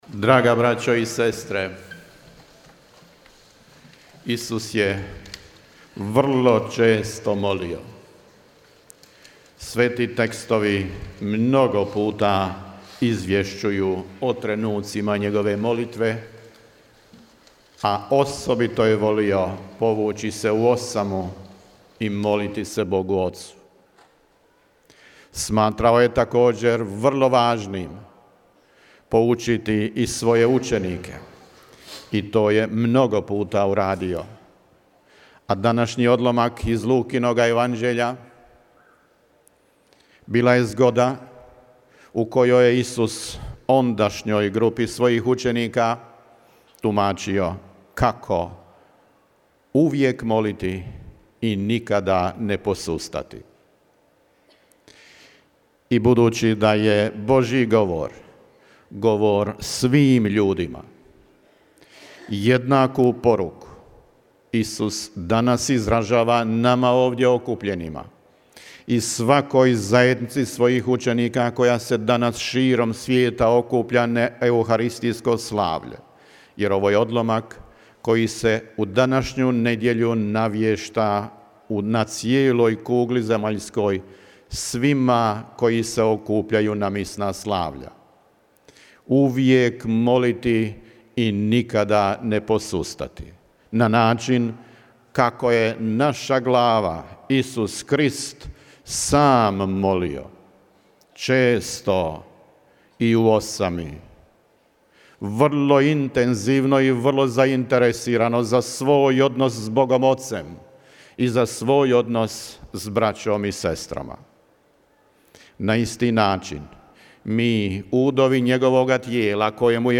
Danas je svetu misu u Međugorju predslavio mons. Tomo Vukšić, vrhbosanski nadbiskup i apostolski upravitelj Vojnog ordinarijata u BiH, a koncelebrirao mu je nadbiskup Aldo Cavalli, apostolski vizitator s posebnom ulogom za župu Međugorje.